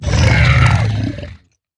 Minecraft Version Minecraft Version snapshot Latest Release | Latest Snapshot snapshot / assets / minecraft / sounds / mob / zoglin / death1.ogg Compare With Compare With Latest Release | Latest Snapshot